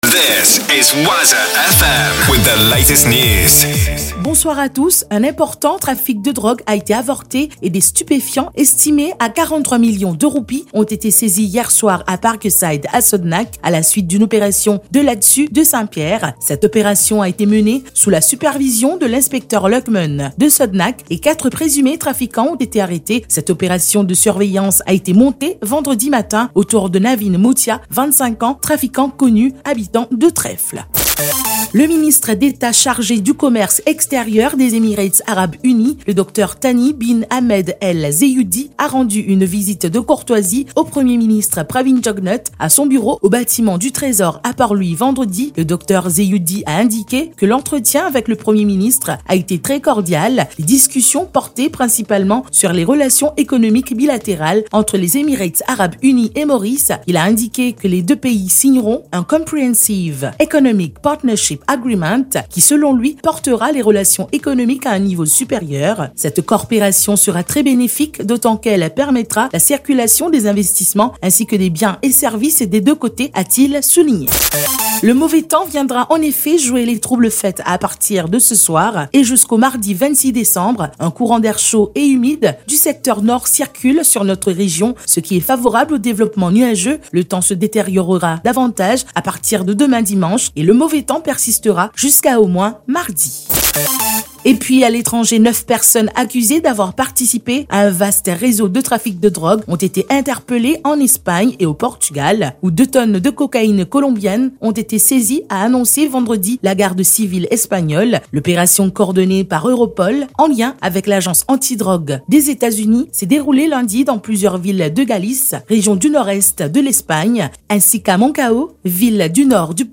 NEWS 20H - 23.12.23